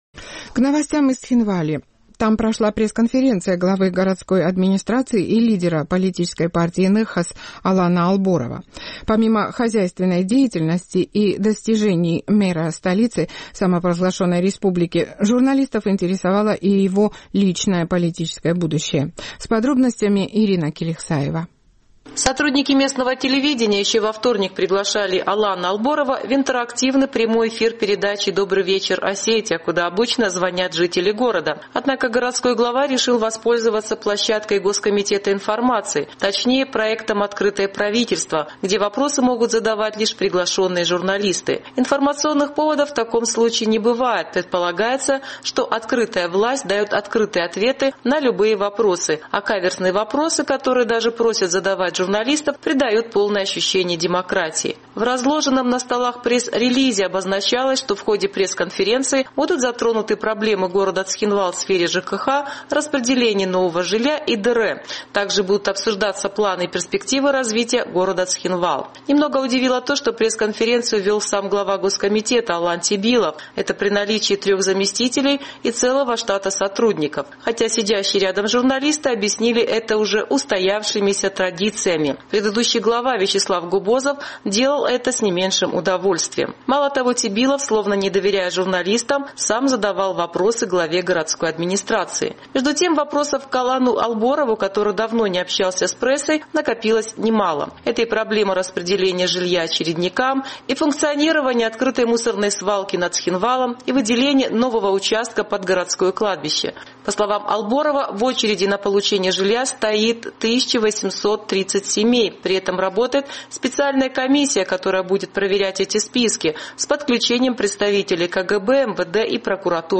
В Цхинвале прошла пресс-конференция главы городской администрации и лидера политической партии «Ныхас» Алана Алборова. Помимо хозяйственной деятельности и достижений мэра на этом посту, журналистов интересовало и его личное политическое будущее.